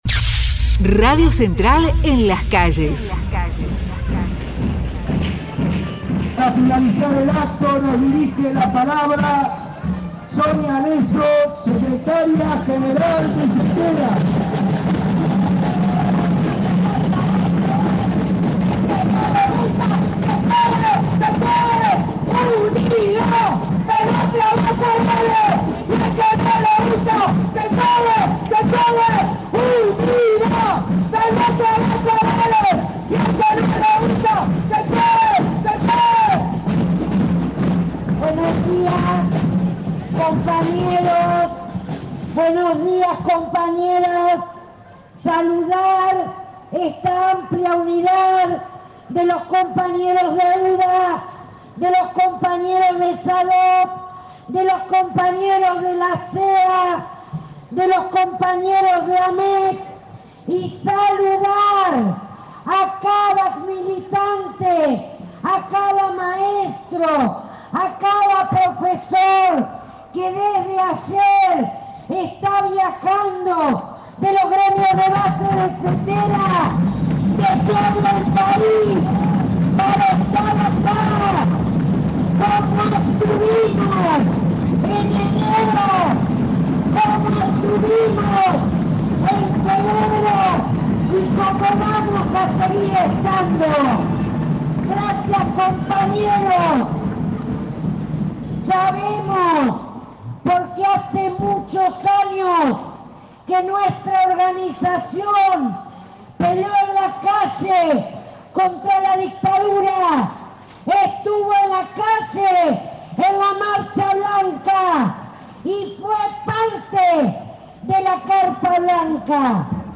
Marcha de los docentes en el Congreso Nacional